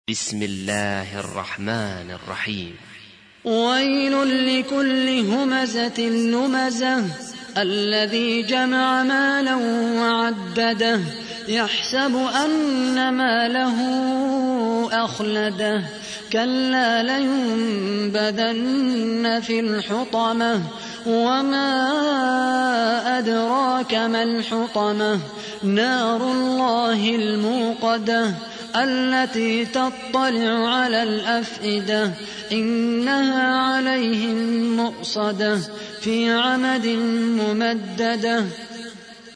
تحميل : 104. سورة الهمزة / القارئ خالد القحطاني / القرآن الكريم / موقع يا حسين